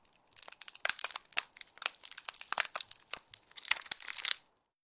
Freezing Effect Sound.wav